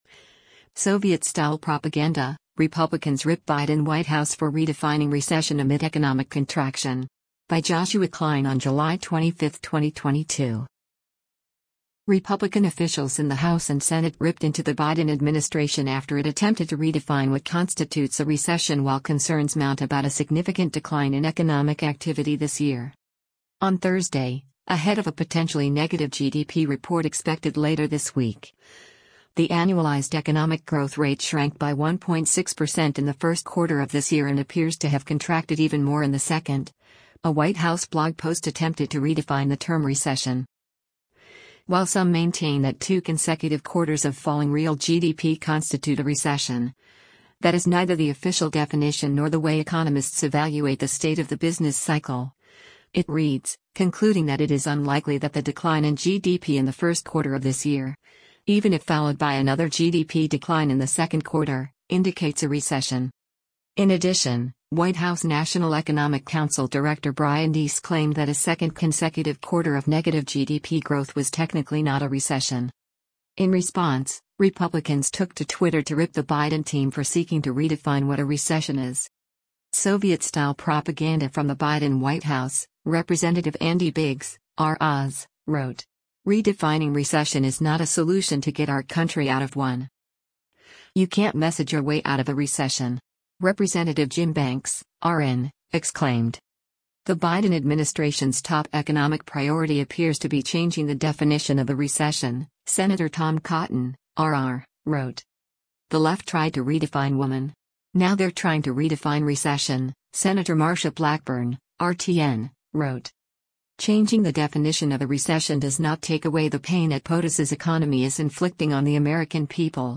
ESG OPEC Schools Housing - Brian Deese, director of the National Economic Council, speaks